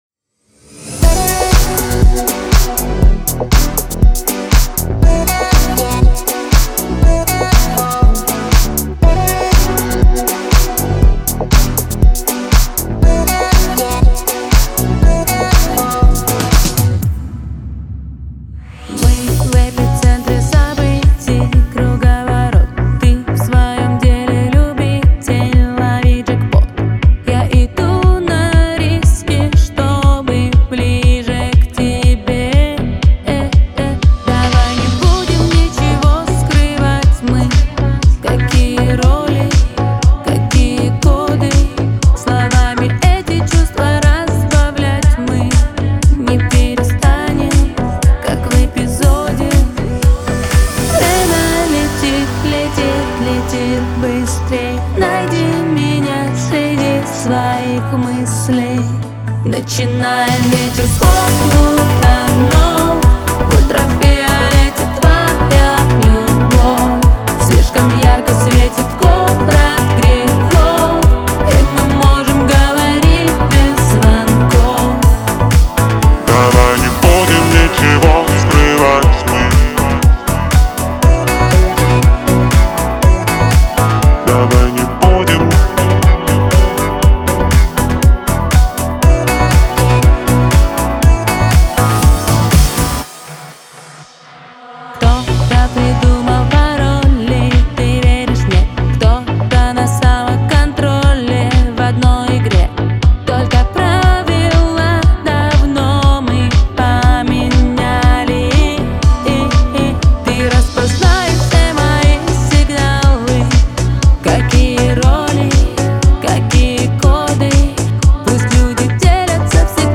мощная композиция в жанре поп-рок